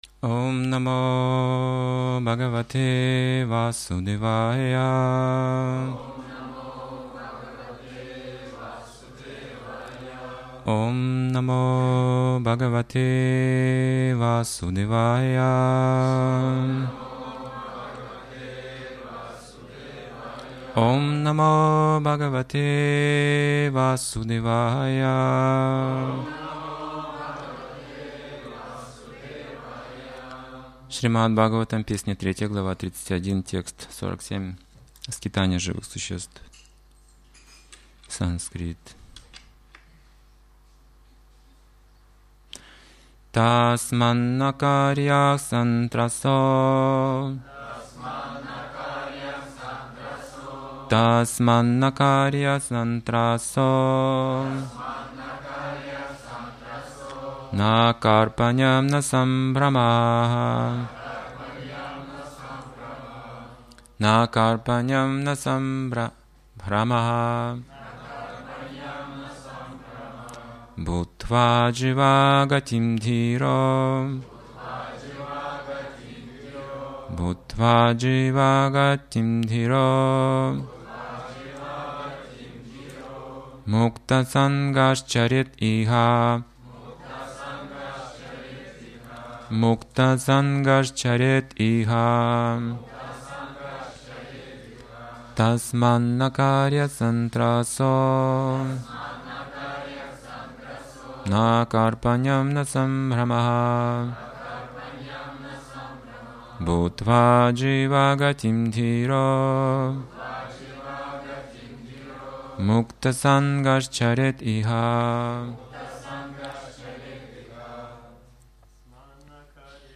Темы, затронутые в лекции: Кто является истинно освобождённой душой?